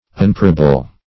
Search Result for " unprayable" : The Collaborative International Dictionary of English v.0.48: Unprayable \Un*pray"a*ble\, a. Not to be influenced or moved by prayers; obdurate.
unprayable.mp3